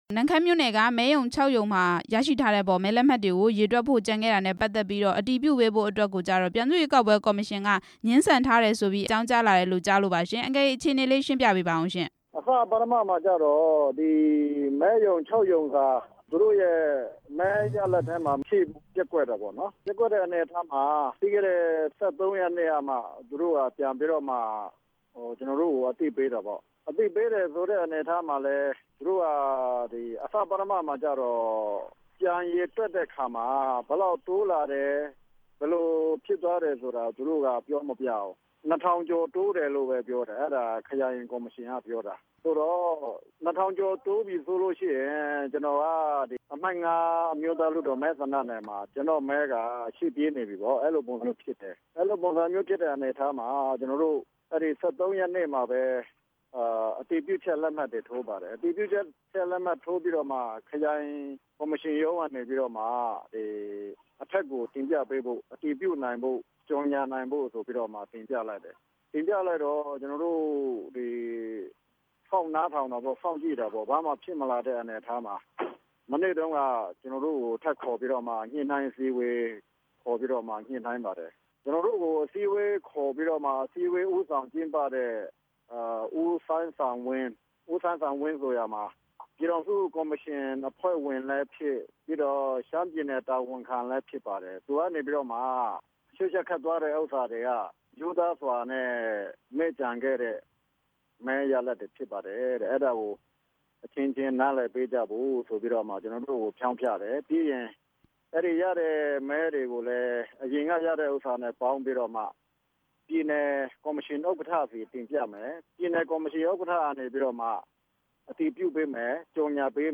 တအာင်း(ပလောင်) အမျိုးသားပါတီရဲ့ အမျိုးသားလွှတ်တော်ကိုယ်စားလှယ်နဲ့ မေးမြန်းချက်